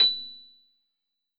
piano-ff-67.wav